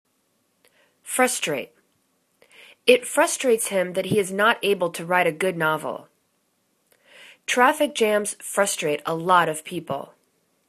frus.trate     /'frustra:t/    v